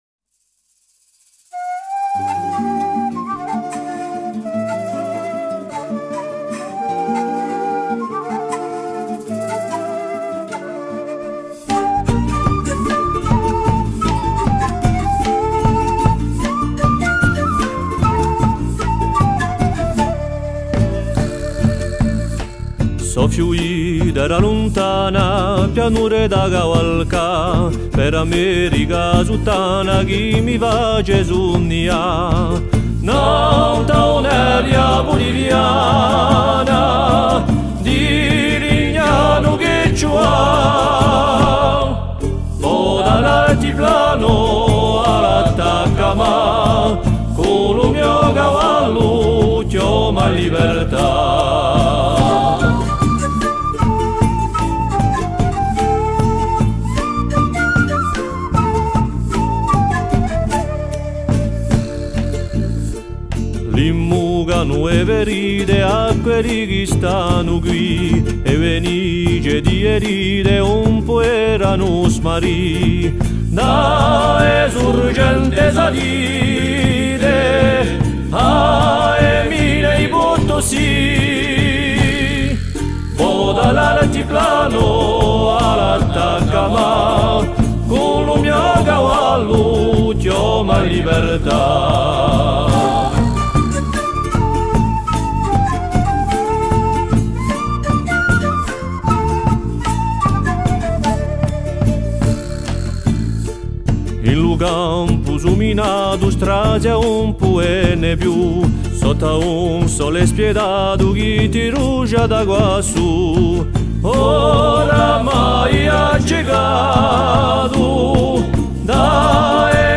GYPAÈTE BARBU / ALTORE
ECOUTER LE CHANT / SENTE U CANTU